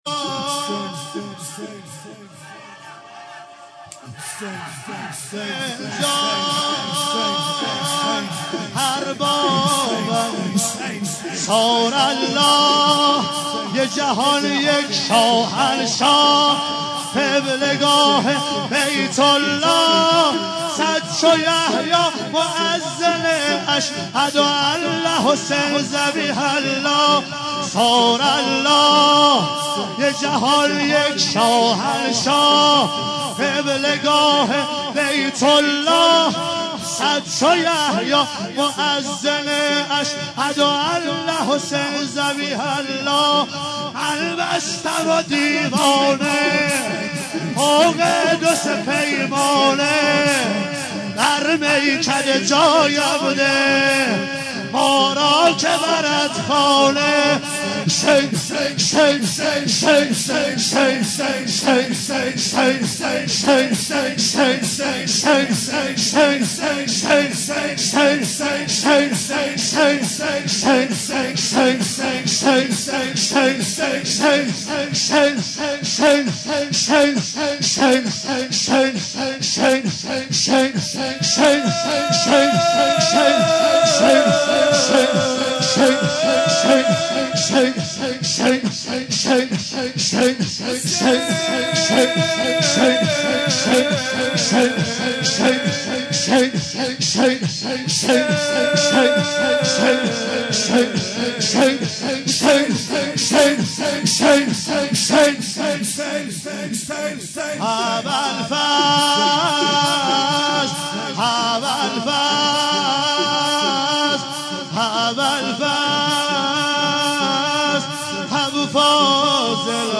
دانلود نوحه، مداحی ، مرثیه و روضه ویژه محرم ۱۳۹۱